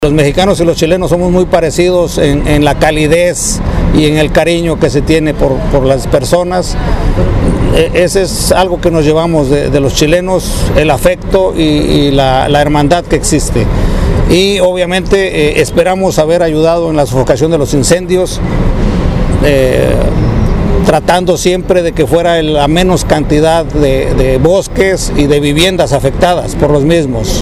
La Plaza Independencia, en el centro de Concepción, fue el escenario de la ceremonia en la que autoridades regionales despidieron a los brigadistas mexicanos y españoles que, durante cuatro semanas, participaron del combate de los incendios forestales en la zona centro sur.